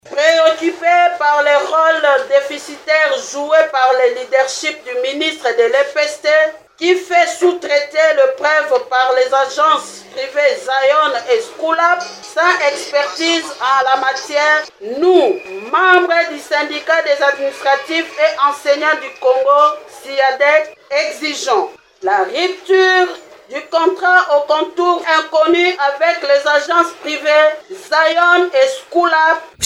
Ils ont entamé cette initiative lors d’une rencontre avec la presse, dans la commune de Kalamu, à Kinshasa.